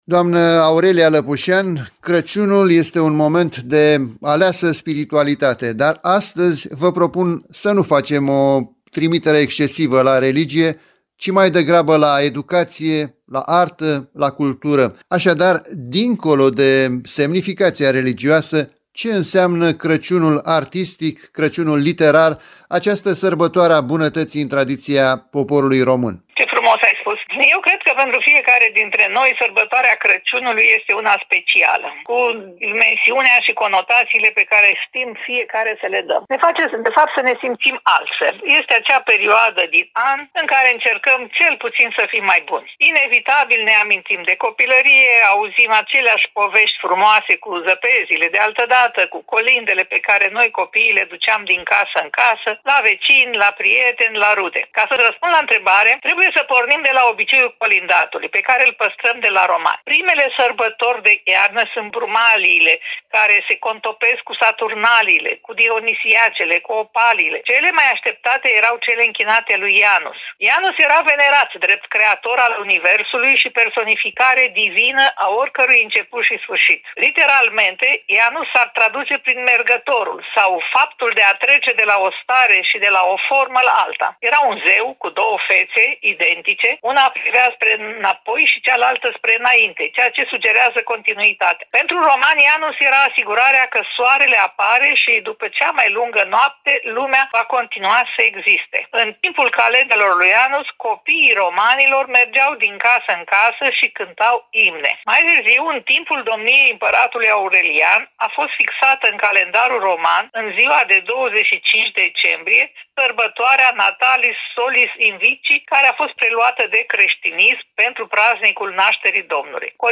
Să „răsfoim” împreună acest dialog de suflet.